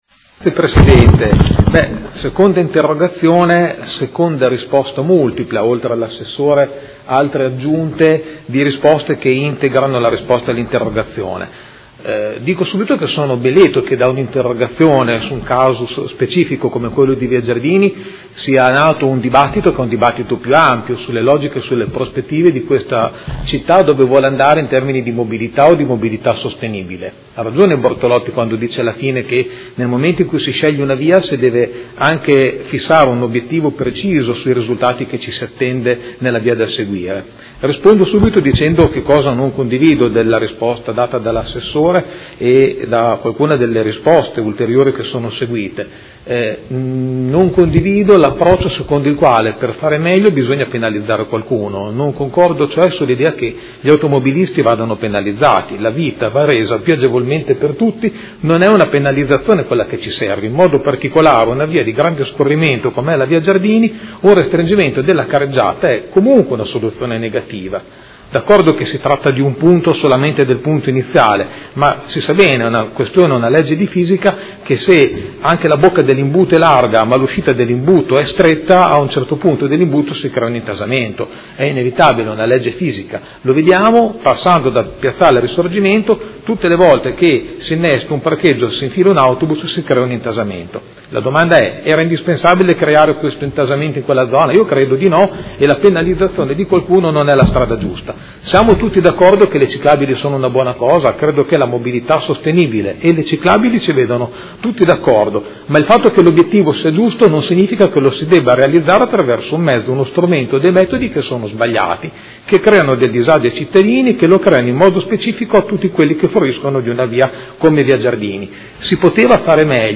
Seduta del 31/03/2016. Interrogazione del Gruppo Consiliare Forza Italia avente per oggetto: Restringimento della carreggiata di Via Pietro Giardini.